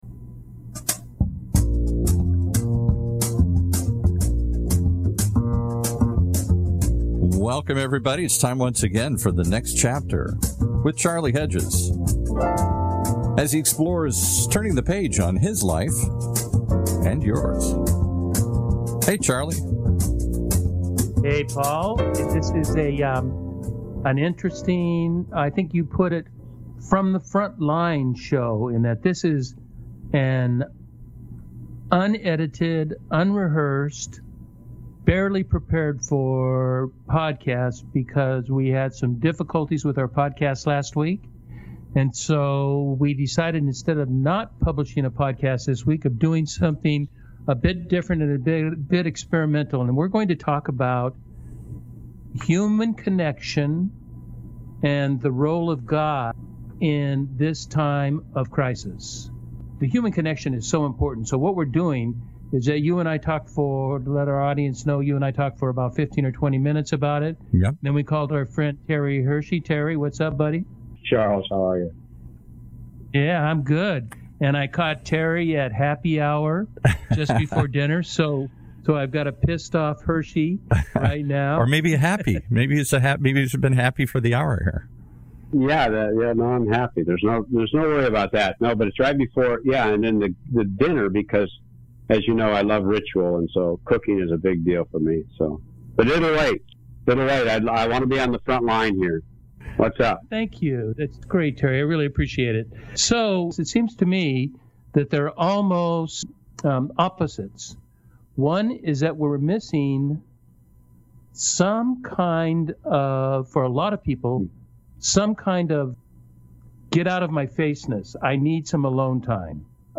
Show Notes Truth is, the original podcast today lost good audio connection in taping, and could not be published today.
No prepared questions with just a little prepared content direction, mostly just a conversation of three guys discussing probably the most awkward time of our entire lives.